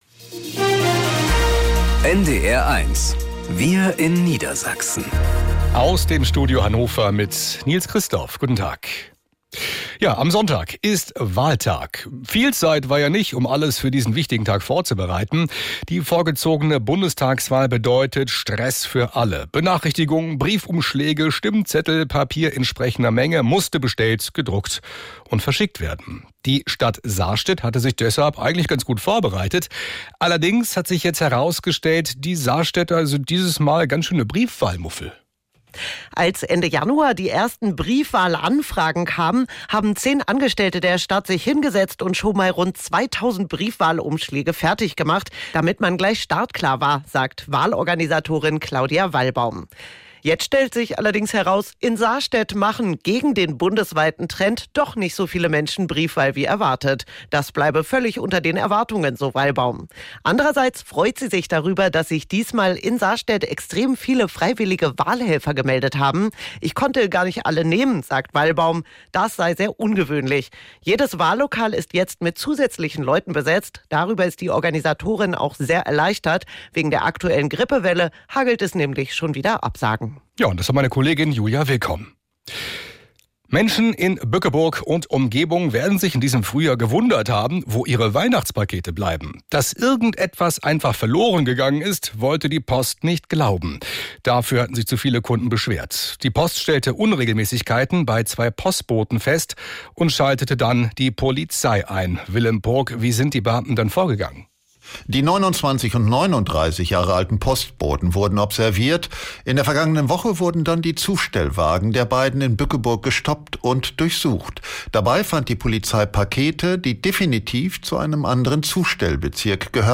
Wir in Niedersachsen - aus dem Studio Hannover | Nachrichten